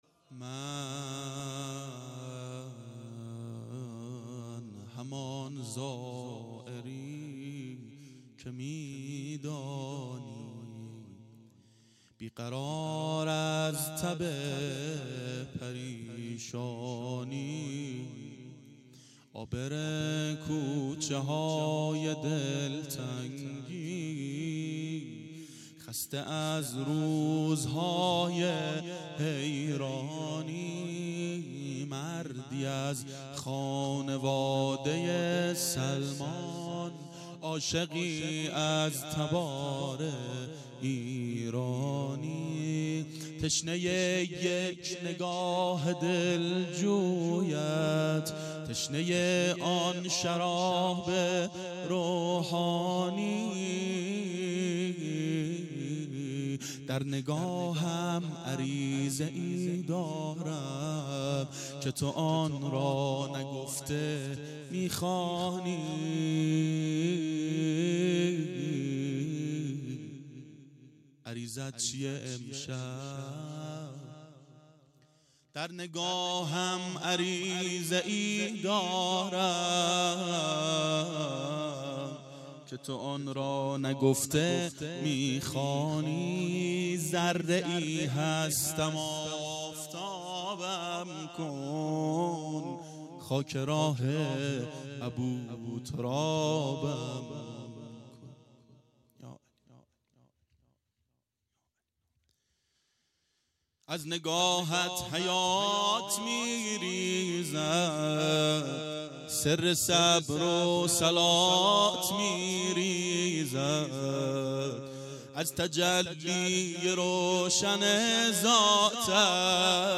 • شب میلاد امیرالمؤمنین حضرت علی علیه السلام 93 عاشقان اباالفضل منارجنبان